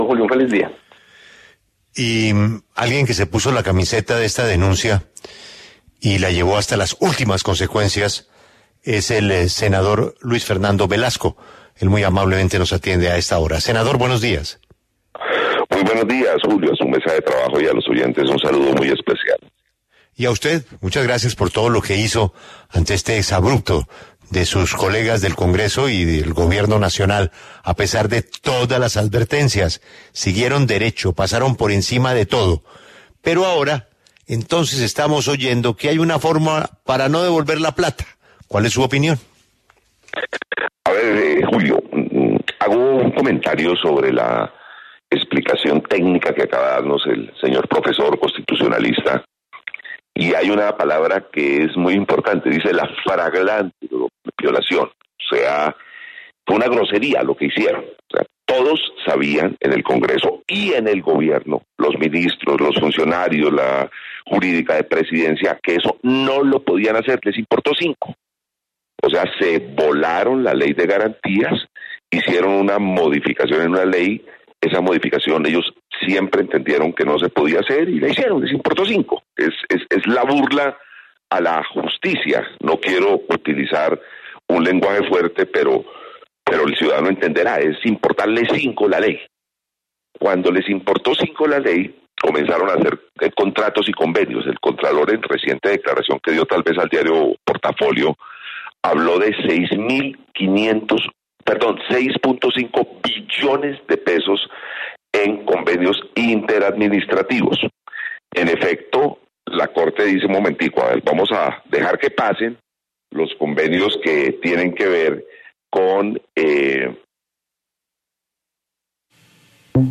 En diálogo con La W, el senador Luis Fernando Velasco hizo duras críticas a la modificación de la Ley de Garantías que permitió la suscripción de convenios en época electoral.